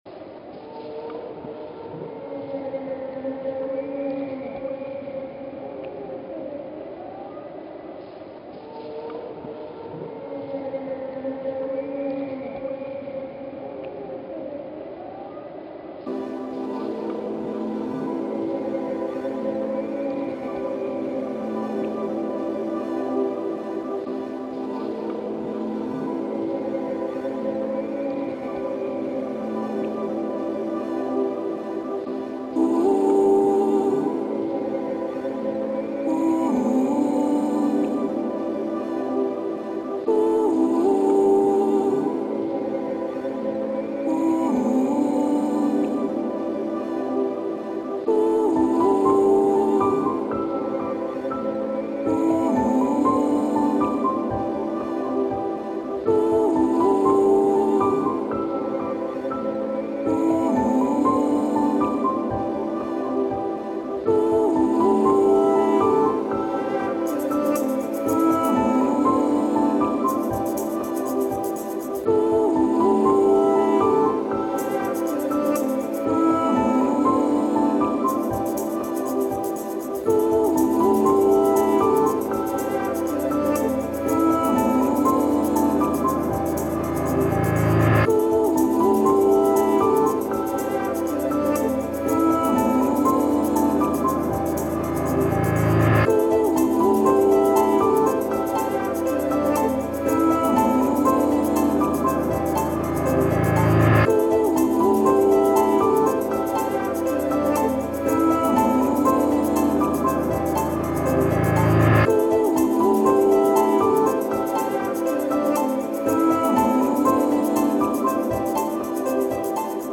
It's a little messy but still pretty soothing.
Genre Ambient